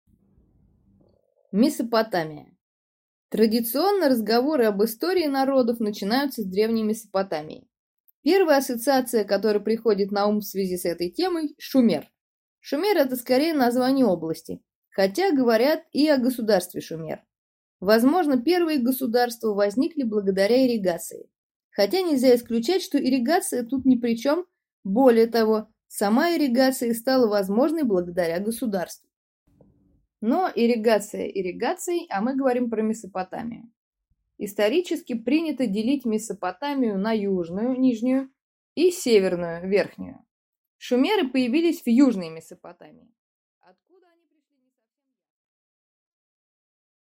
Аудиокнига Шумер и Аккад | Библиотека аудиокниг